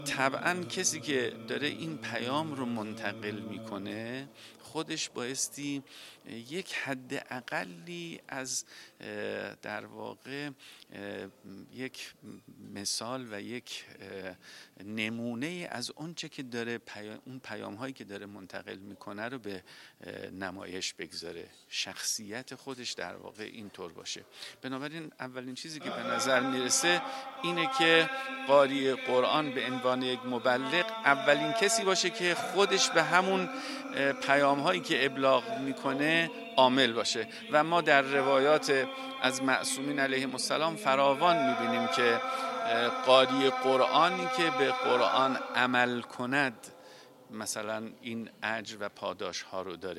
با این وجود در حاشیه این نشست، ویژگی‌هایی که یک قاری مبلغ باید داشته باشد از سه تن از کارشناسان پیشکسوت قرآن جویا شدیم که در ادامه اظهار نظر ایشان را می‌خوانید: